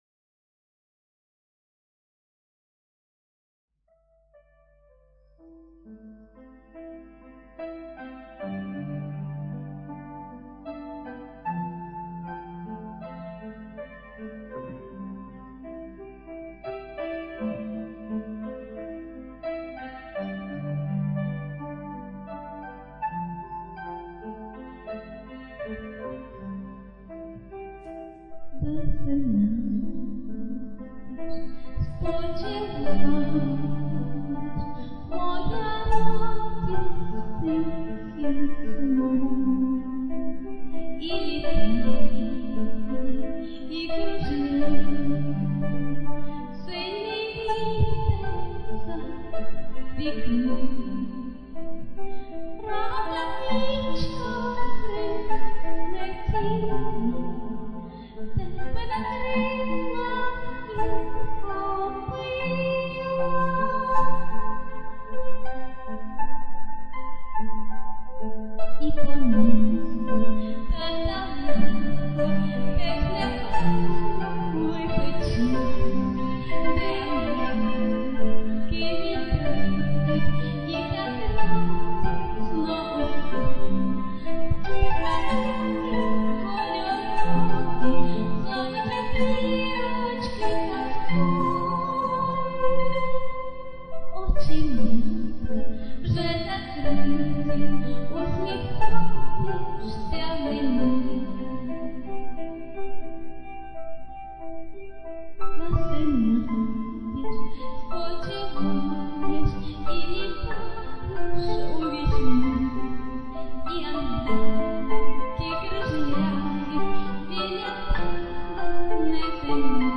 Рубрика: Поезія, Лірика
Такий гарний, ніжний голос, заспокоює... 39 39 39 give_rose